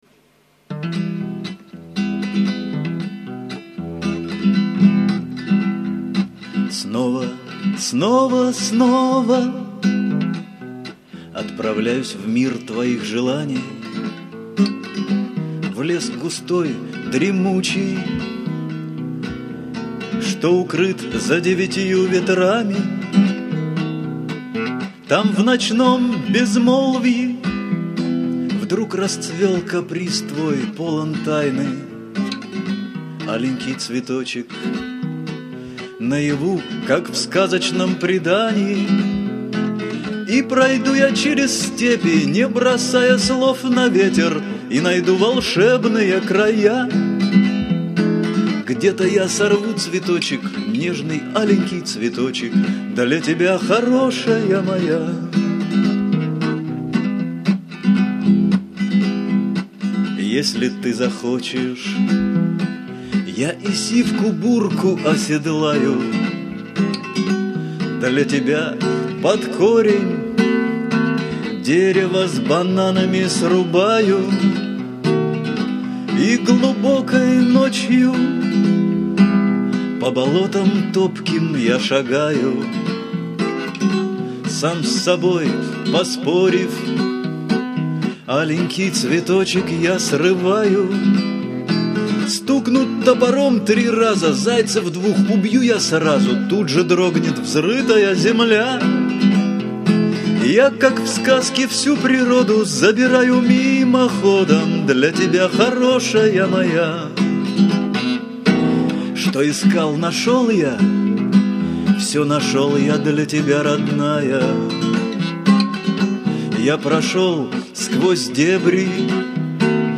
Шансон
Такой гитарный вариант